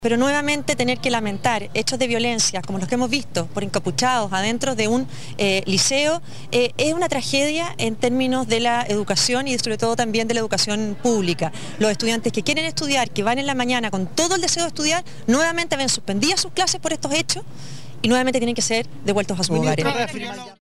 La ministra de Educación, Marcela Cubillos, indicó que el suceso es una “tragedia” para la educación pública.